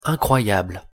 Nasal
“in” + consonantincroyableɛ̃kʀwajabl
incroyable-pronunciation.mp3